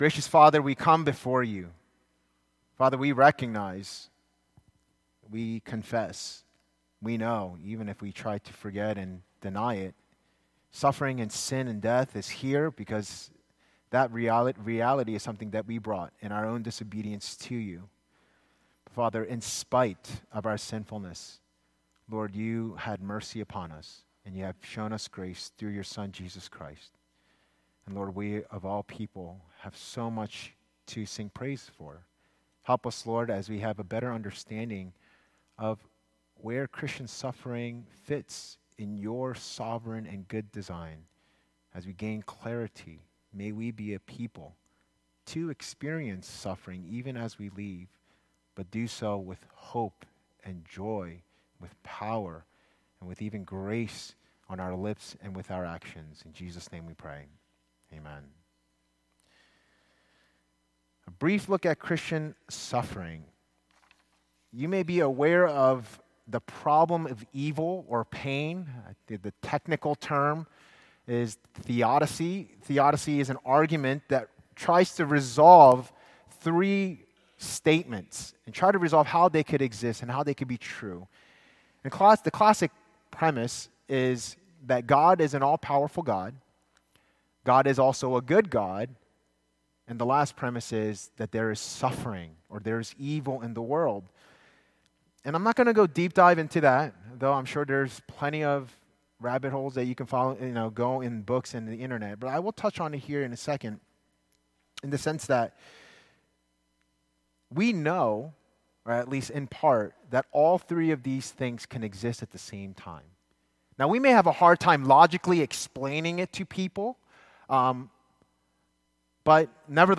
A Brief Look at Christian Suffering - Redeemer Presbyterian Church, Overland Park, KS